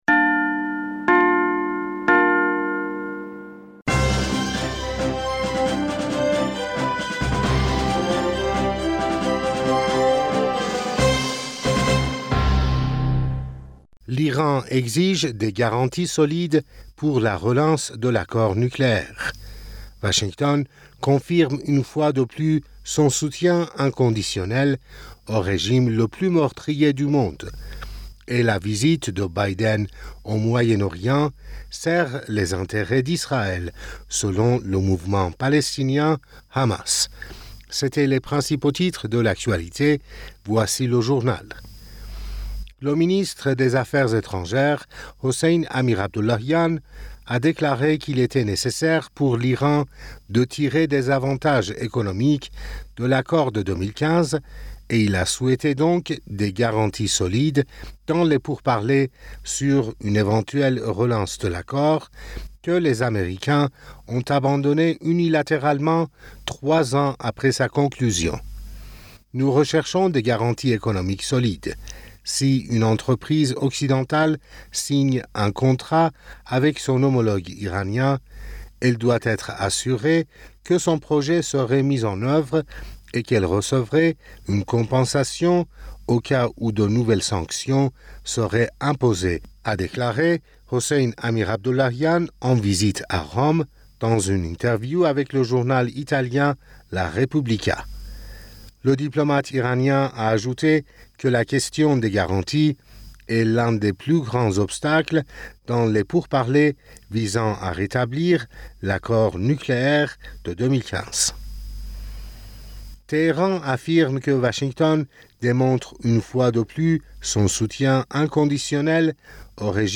Bulletin d'information Du 14 Julliet